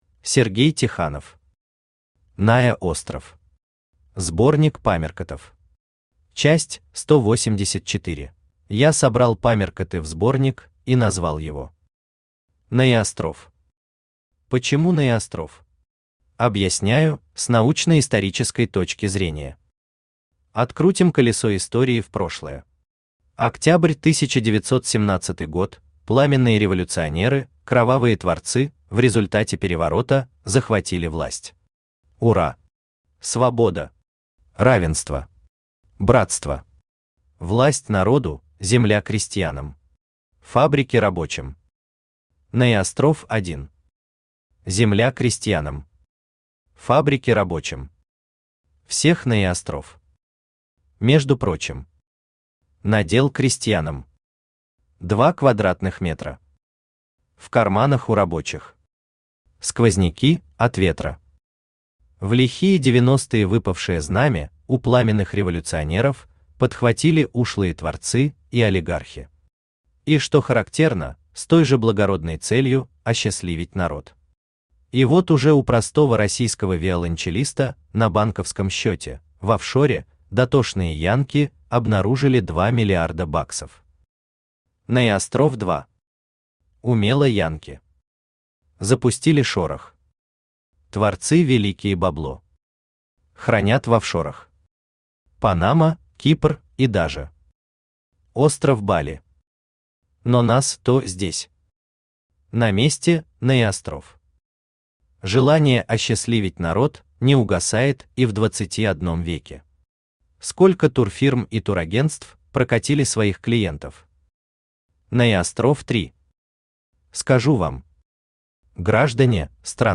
Аудиокнига НаеОстров. Сборник памяркотов. Часть 184 | Библиотека аудиокниг